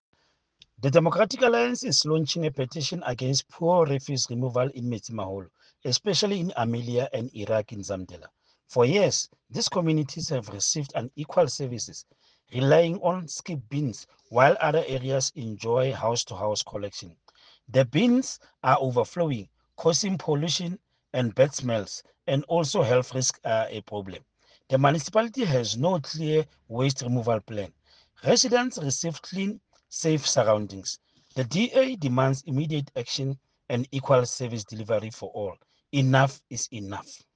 Sesotho soundbites by Cllr Stone Makhema and